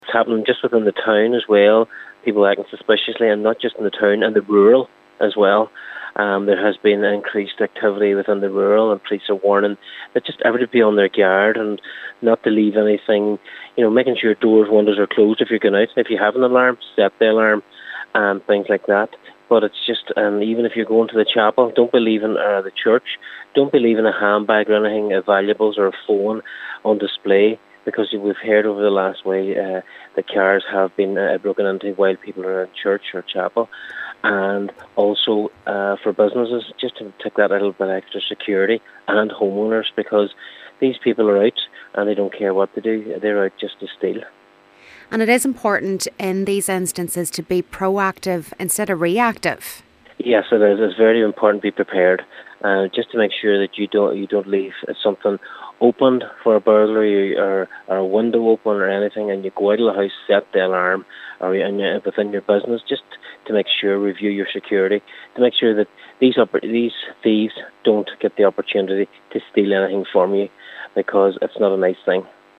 Local Cllr Patsy Kelly says it’s important to be proactive instead of reactive: